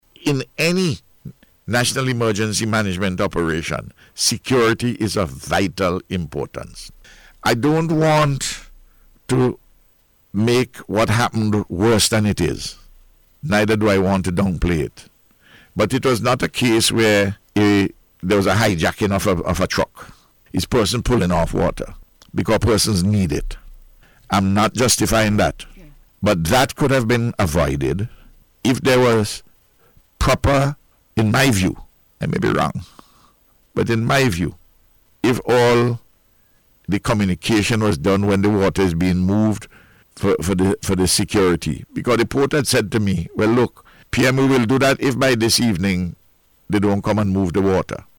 He made the point, during his daily update on NBC Radio on Thursday, as he spoke about an incident which occurred on Wednesday, where people were seen removing cases of water earmarked for relief efforts, from a truck, in two separate instances in capital Kingstown.